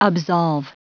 Prononciation du mot absolve en anglais (fichier audio)
Prononciation du mot : absolve